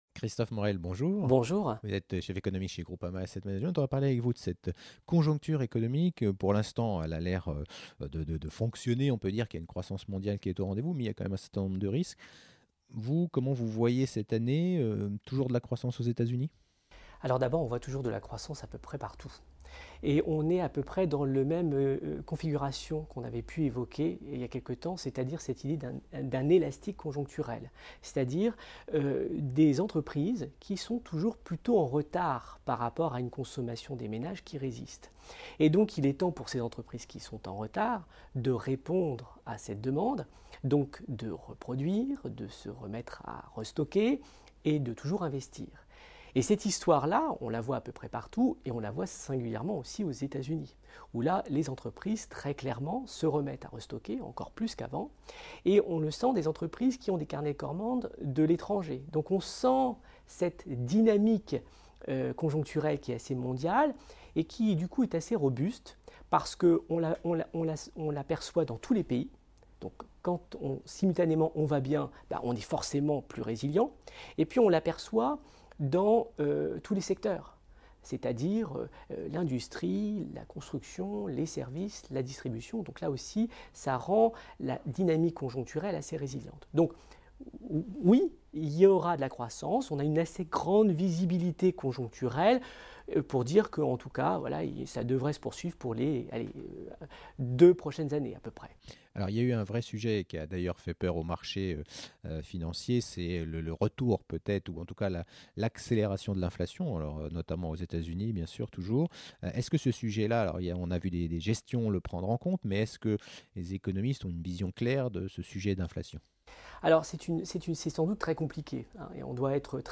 Tour d’horizon des questions d’actualités avec mon invité